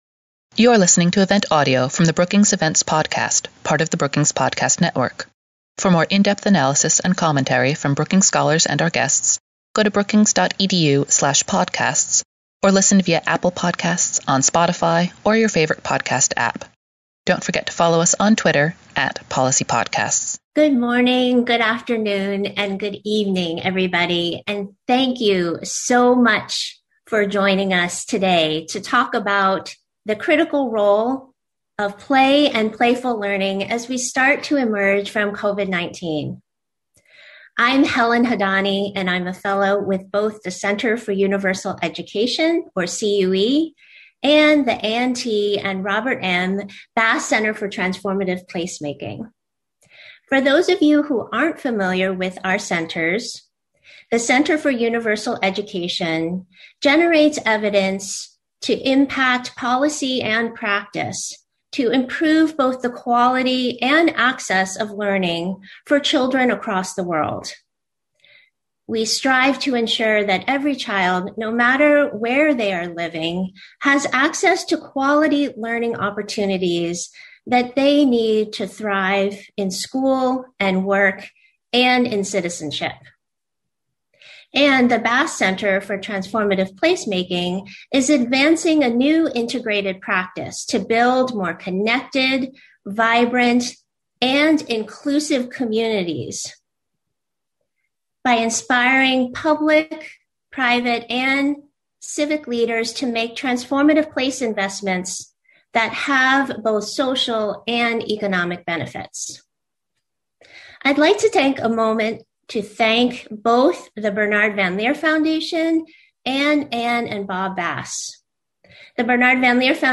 On May 6, the Bass Center for Transformative Placemaking and the Center for Universal Education at Brookings will host an online event on Why children and cities need play now more than ever.